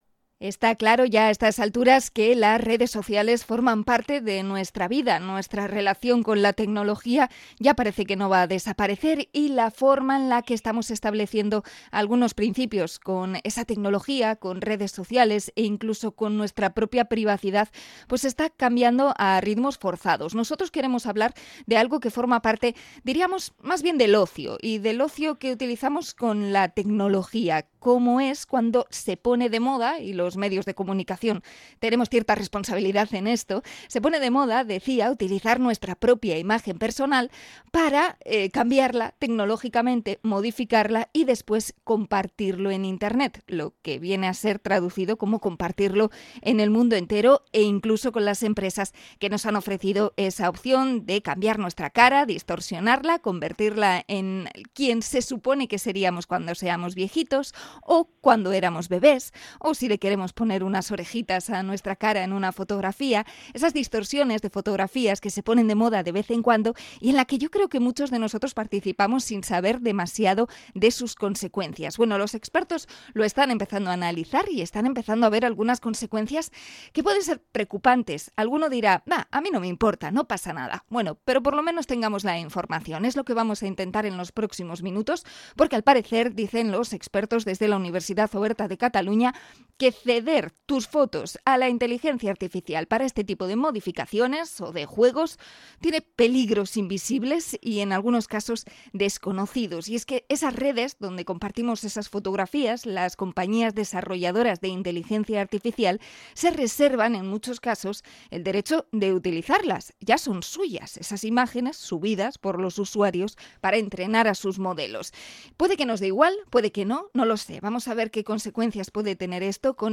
Entrevista a experto en privacidad en Redes Sociales